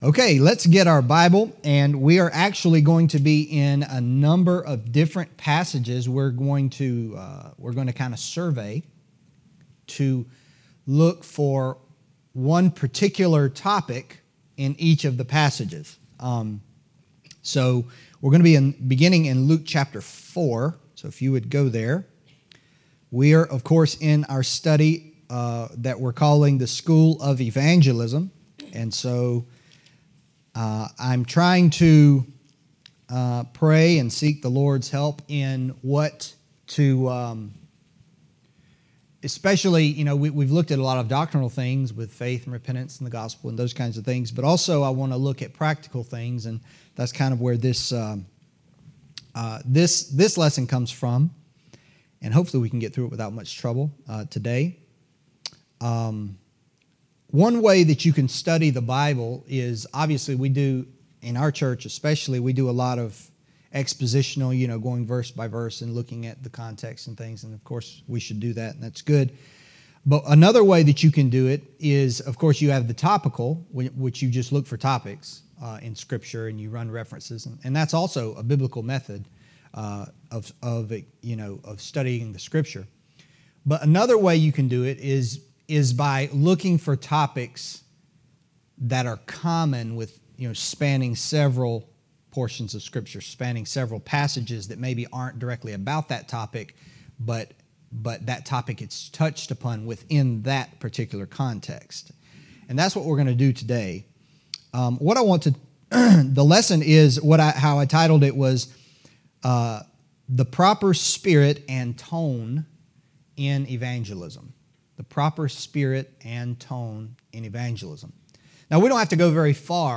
Adult Sunday School: School of Evangelism &middot